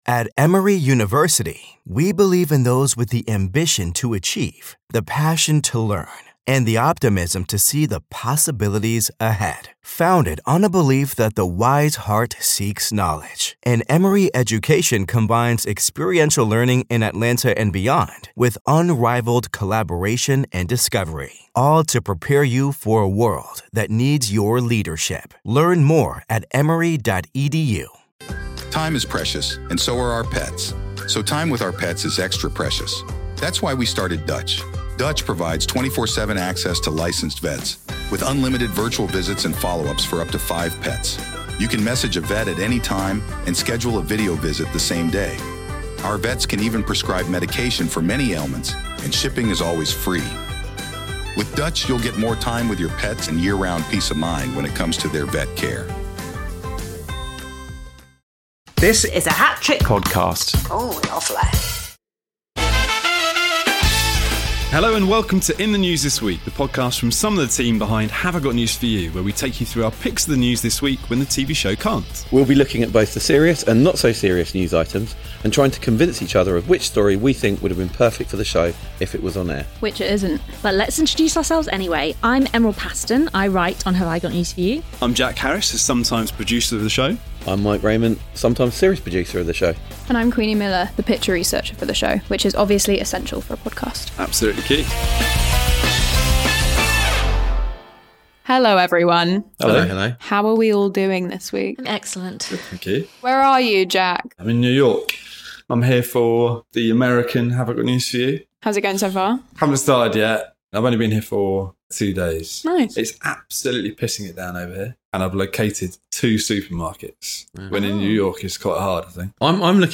Join some of the team behind Have I Got News For You as they take you through the serious (but more often not-so-serious) stories In The News This Week.